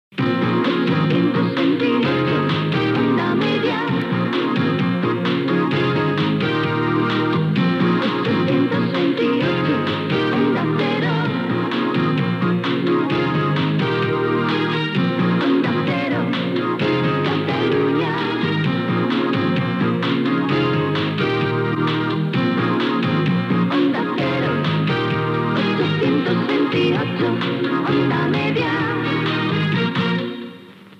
Indicatiu cantat de l'emissora a la freqüència 828 KHz OM